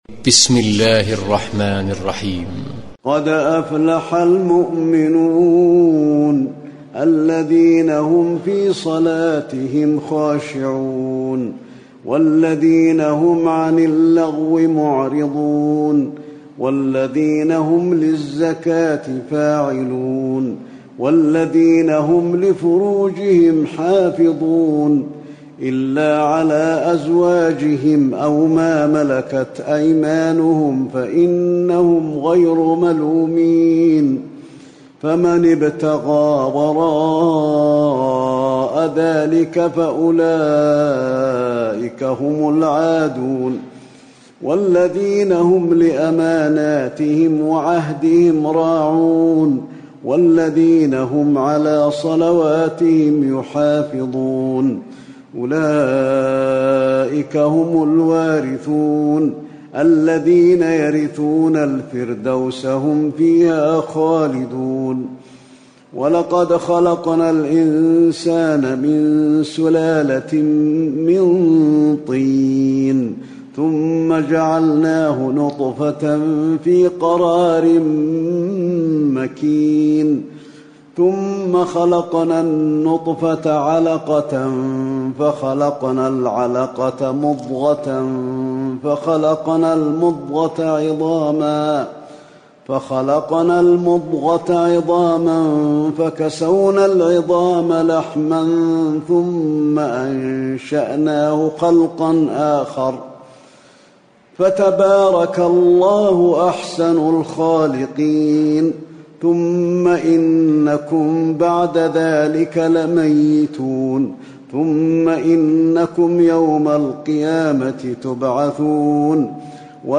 تراويح الليلة السابعة عشر رمضان 1437هـ سورتي المؤمنون و النور (1-20) Taraweeh 17 st night Ramadan 1437H from Surah Al-Muminoon and An-Noor > تراويح الحرم النبوي عام 1437 🕌 > التراويح - تلاوات الحرمين